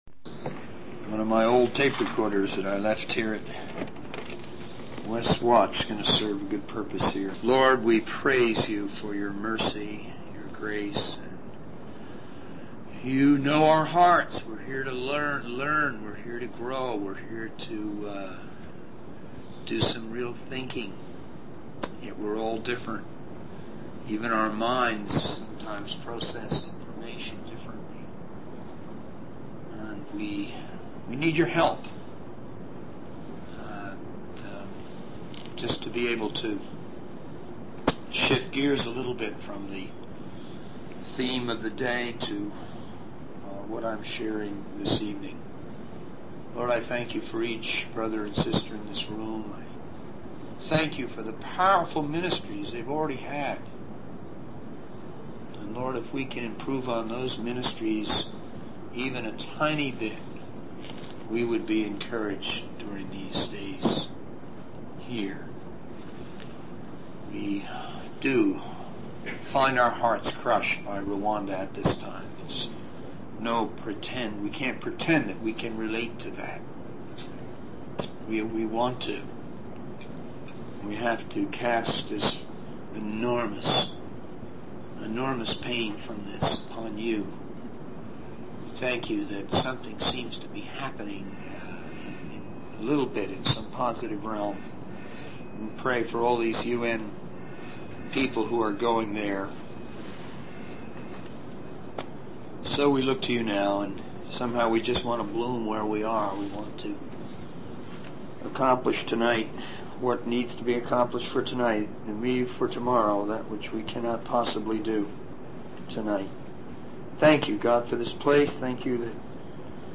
In this sermon, the speaker discusses the distribution of evangelistic materials, such as Jesus films and gospel tracks, in Canada and around the world.